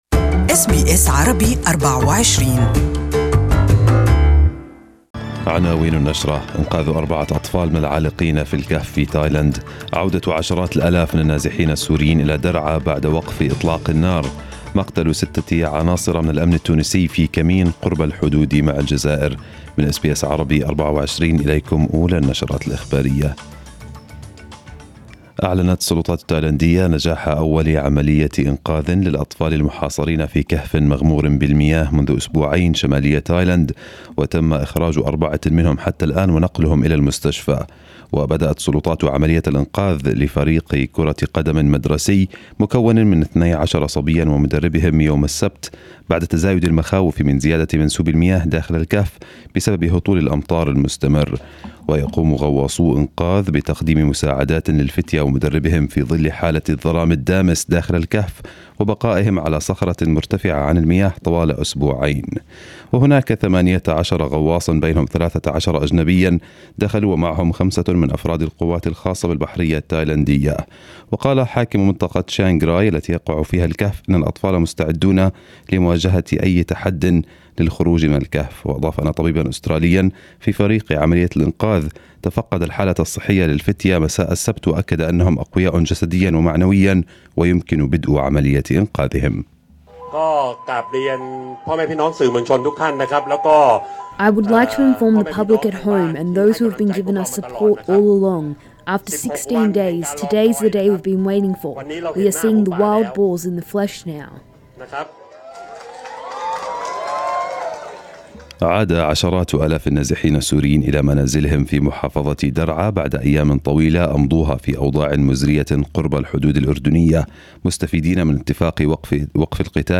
Arabic News Bulletin 09/07/2018